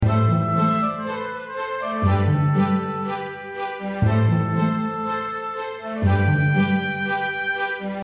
10秒BGM （111件）